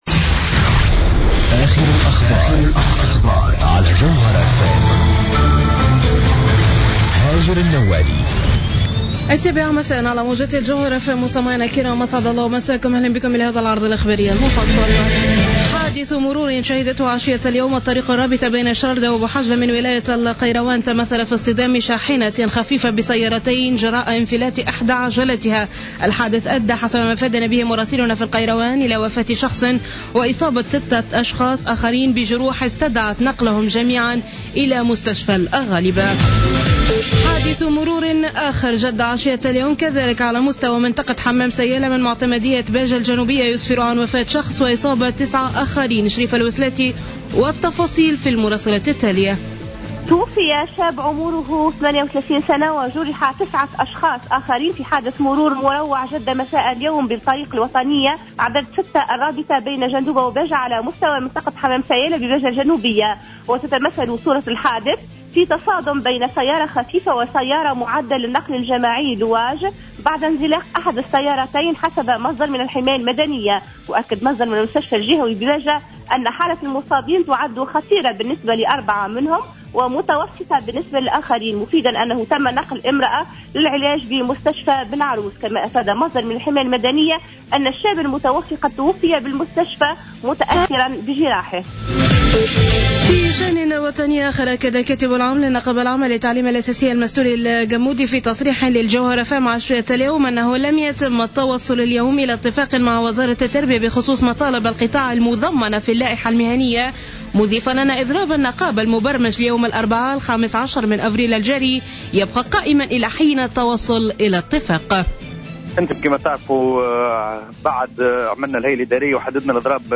نشرة أخبار السابعة مساء ليوم السبت 11 أفريل 2015